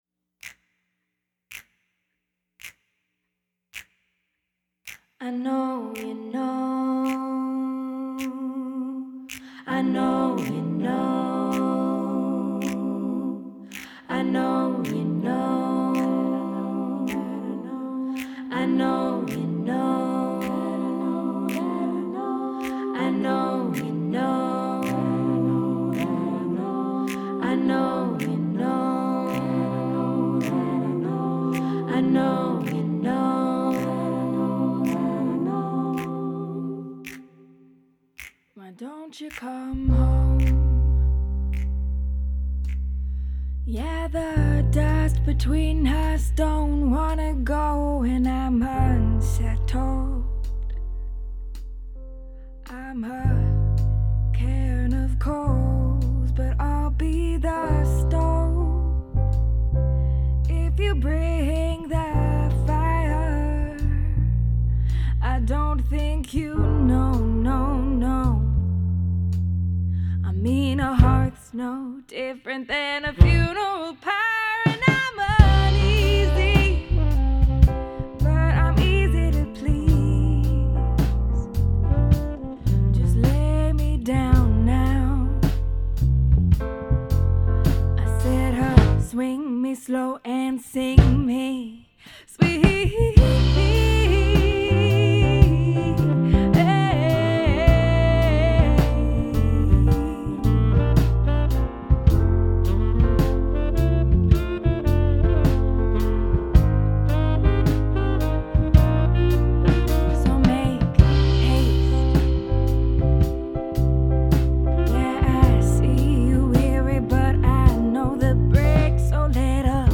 Violoncelliste de formation et pianiste autodidacte